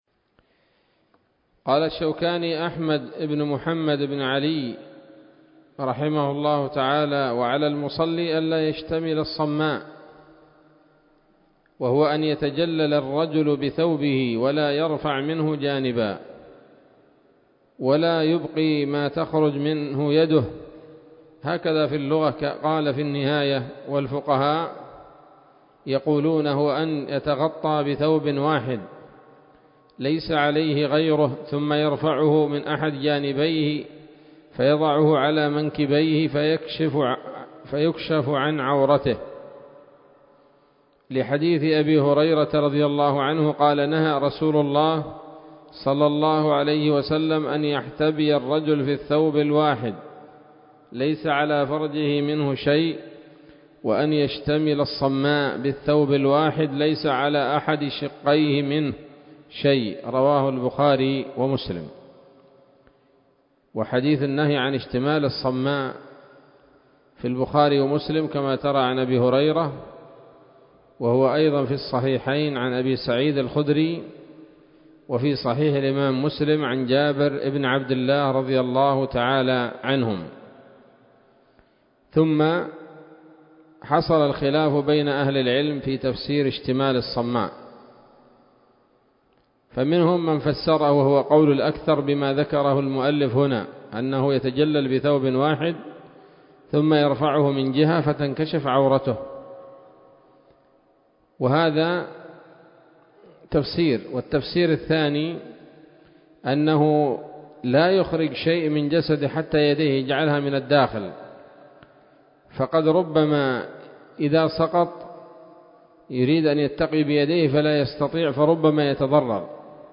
الدرس العاشر من كتاب الصلاة من السموط الذهبية الحاوية للدرر البهية